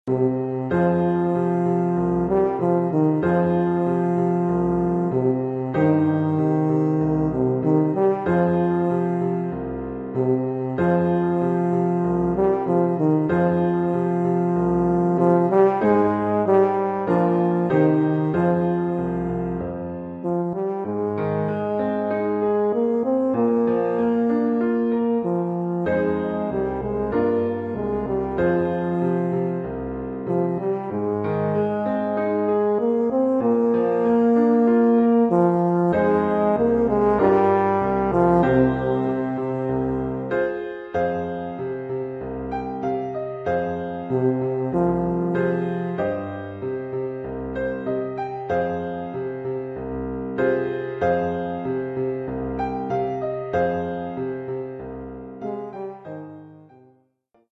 Oeuvre pour saxhorn basse /
euphonium / tuba et piano.
Niveau : débutant (1er cycle, 2e année).